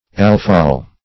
Search Result for " alphol" : The Collaborative International Dictionary of English v.0.48: Alphol \Al"phol\, n. [Alpha- + -ol as in alcohol.]